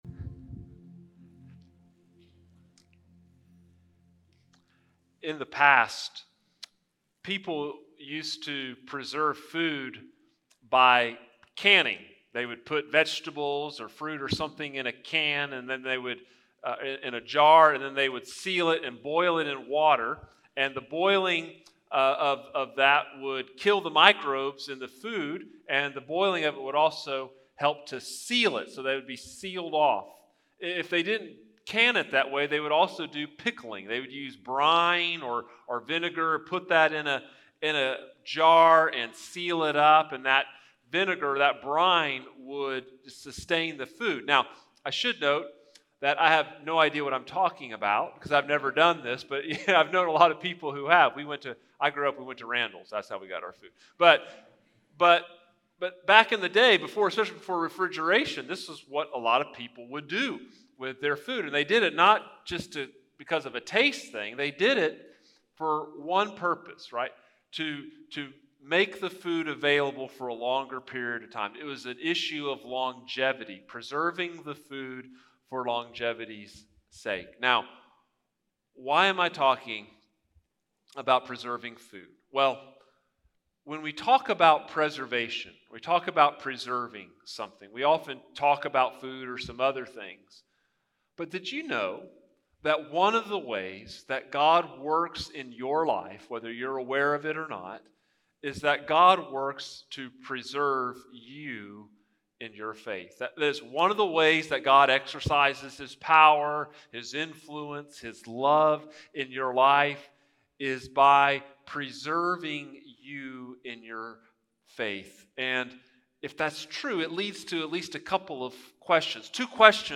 Sermons | First Baptist Church, Brenham, Texas